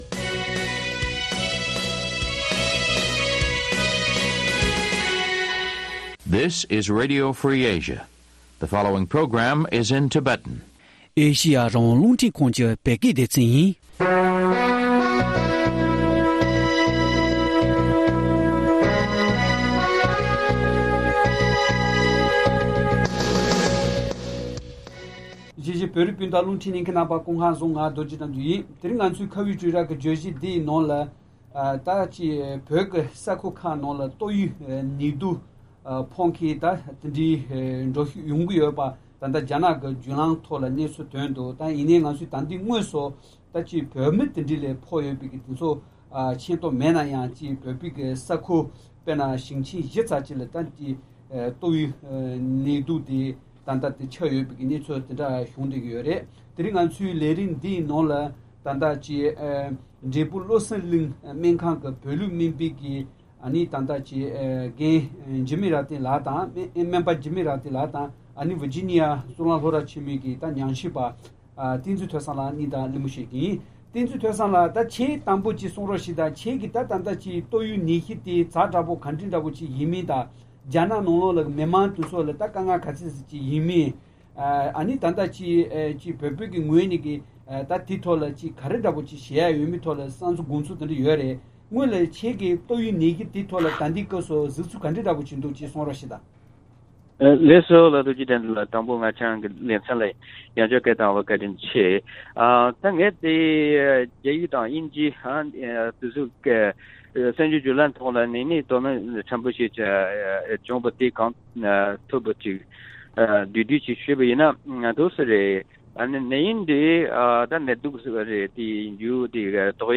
དེ་བཞིན་ཨ་རིའི་གཞུང་གིས་ངོས་ནས་ཀྱང་རྒྱ་ནག་ལ་རོགས་པ་གང་དགོས་གནང་རྒྱུ་ཡིན་ཟེར་བ་སོགས་རྒྱ་ནག་གིས་ནད་ཡམས་དེ་བཀག་བསྡོམས་ཐད་ཕྱི་ཕྱོགས་ནས་རོགས་པ་བསྟེན་དགོས་གལ་ཆེན་སྐོར་འབྲེལ་ཡོད་དང་གླེང་མོལ་ཞུས་པ་ཞིག་ཉན་རོགས་ཞུ།།